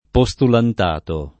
vai all'elenco alfabetico delle voci ingrandisci il carattere 100% rimpicciolisci il carattere stampa invia tramite posta elettronica codividi su Facebook postulantato [ po S tulant # to ] s. m. (eccl.) — non postulandato